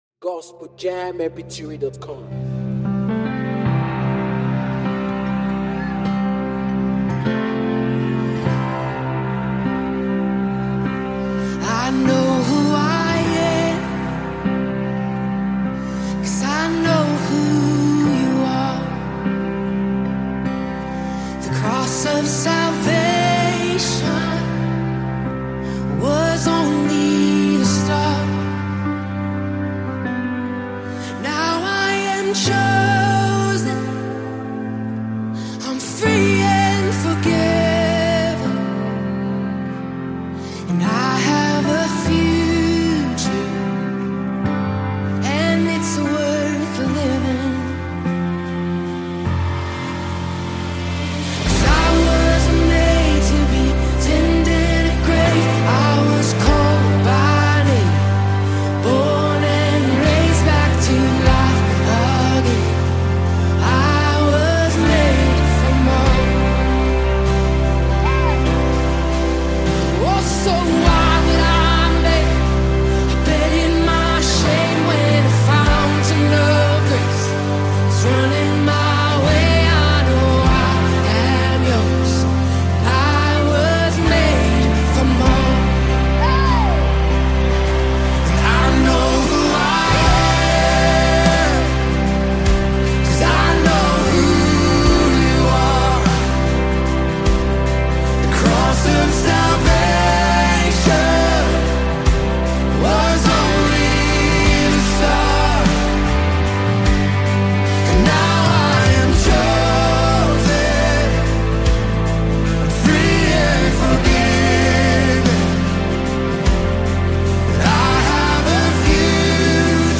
Popular Gospel Group